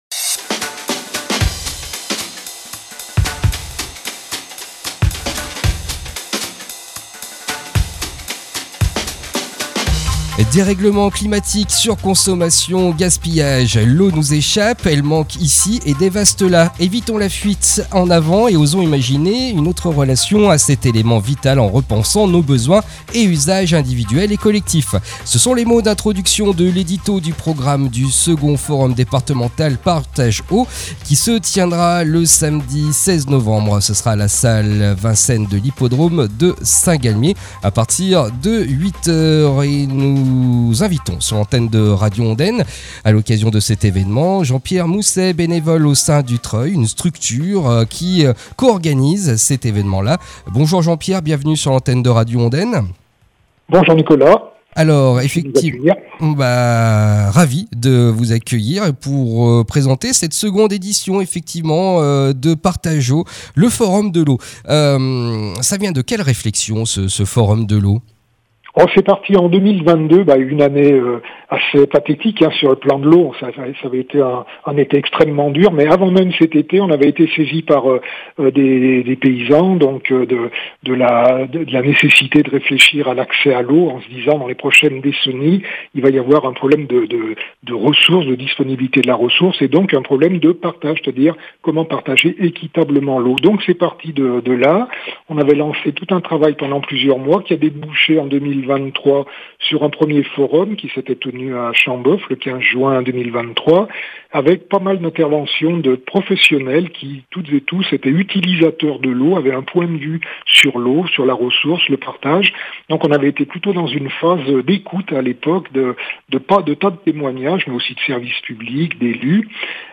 Rencontre aujourd’hui sur Radio Ondaine